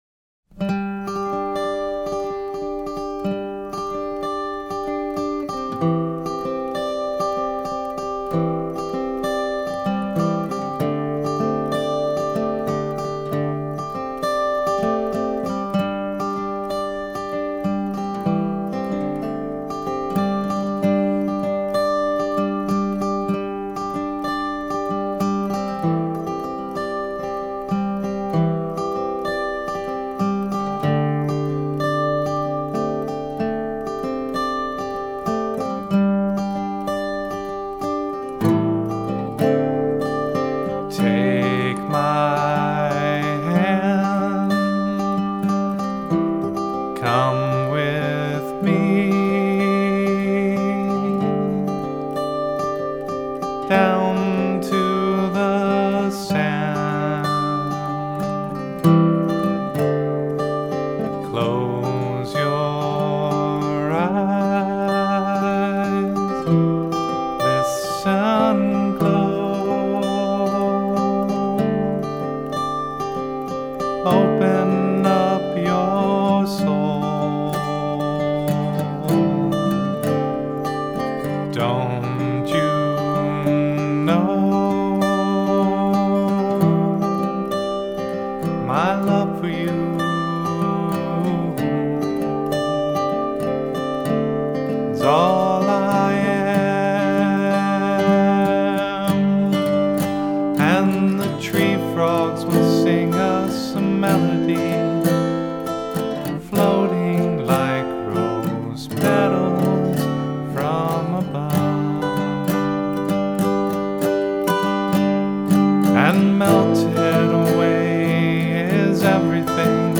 Vocals, guitars